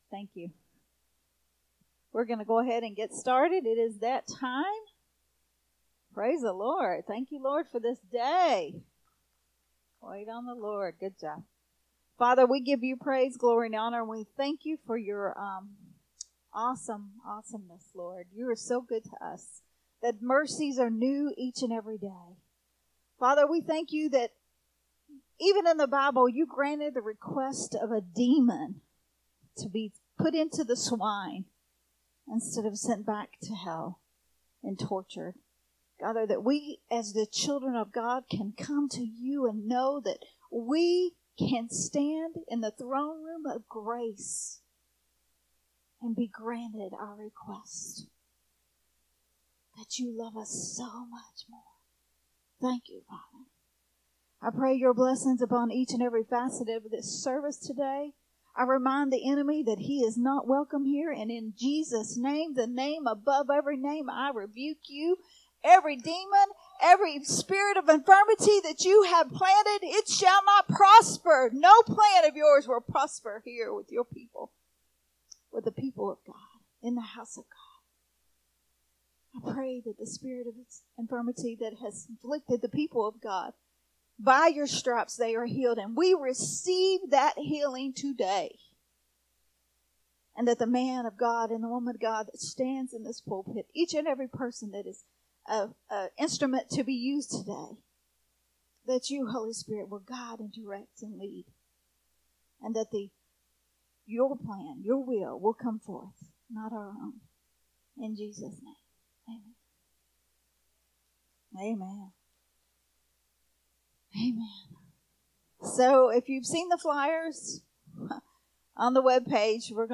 a Sunday Morning Risen Life teaching
recorded at Unity Worship Center on Sunday